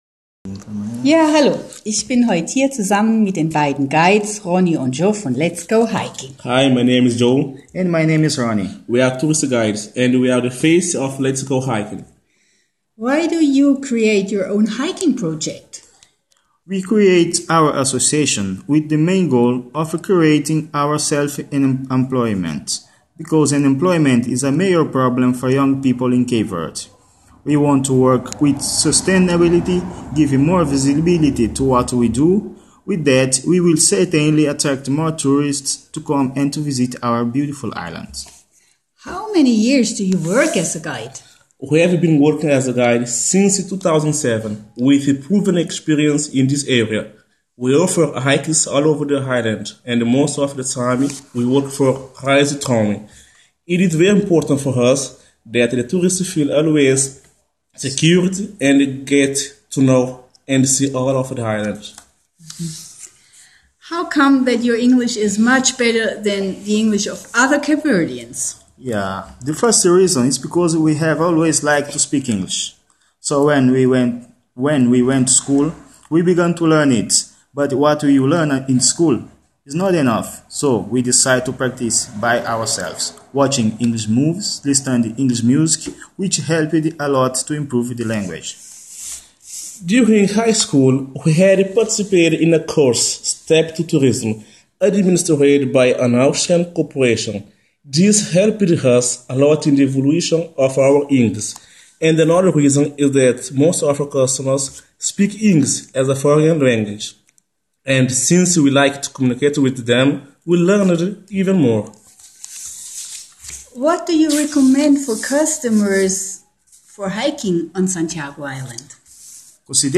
Die beiden Guides beantworten auf Englisch die folgenden Fragen
Interview.mp3